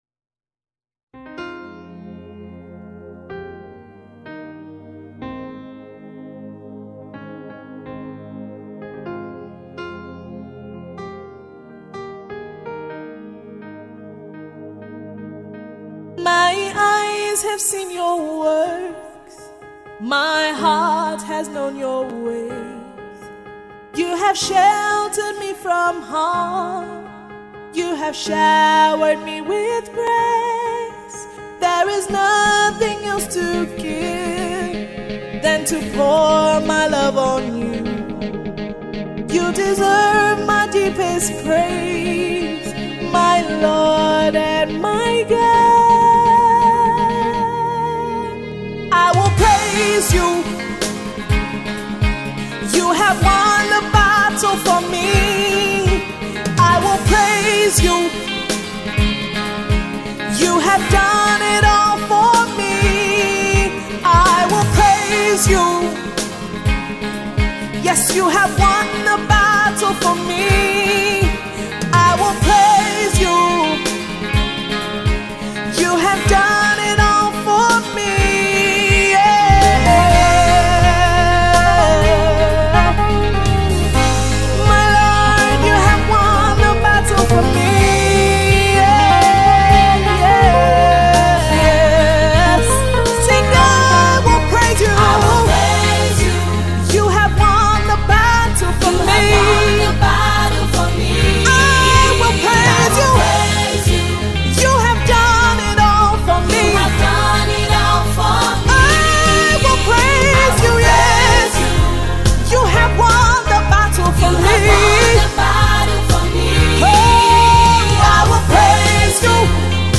Nigerian gospel
Tags: Gospel Music,